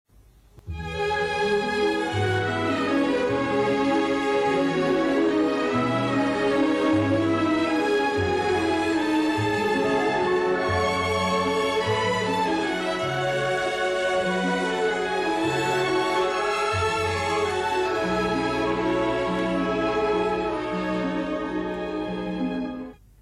Suite in 4 Sätzen für Kleines Orchester